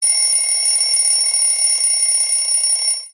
Category Message